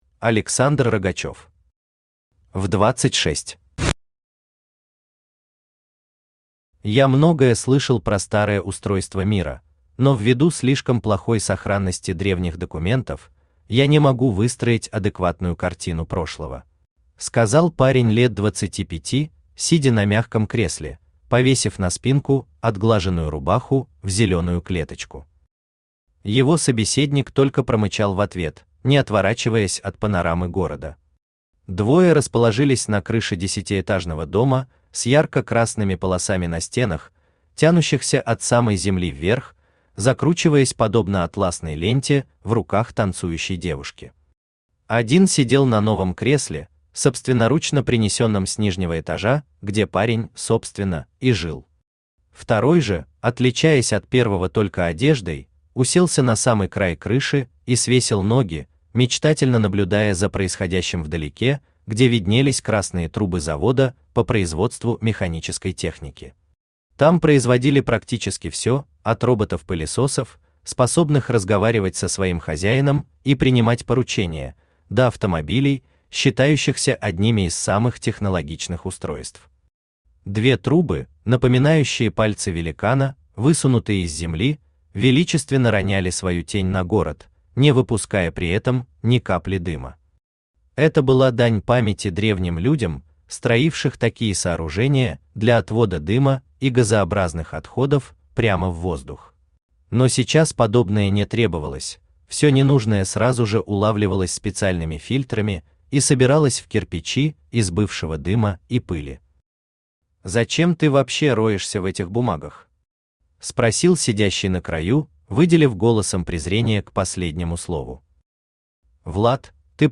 Аудиокнига В26 | Библиотека аудиокниг
Aудиокнига В26 Автор Александр Рогачев Читает аудиокнигу Авточтец ЛитРес.